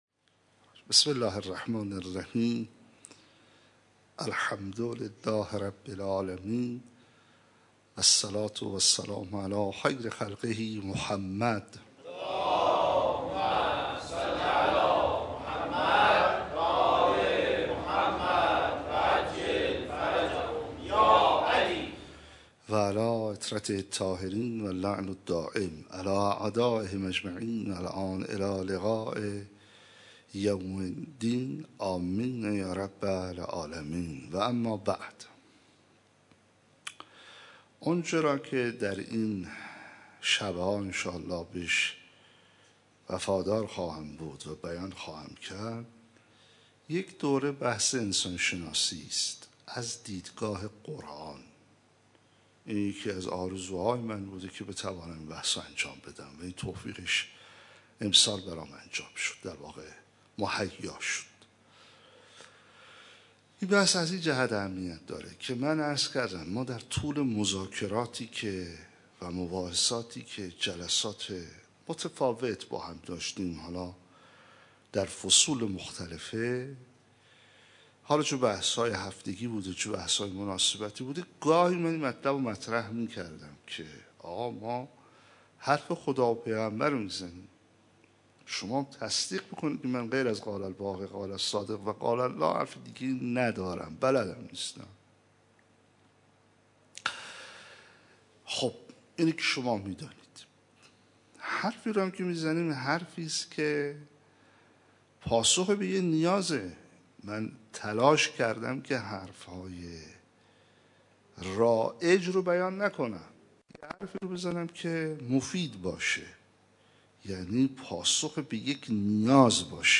همزمان با اول ماه مبارک رمضان پیرامون موضوع انسان شناسی در مسجد حضرت امیر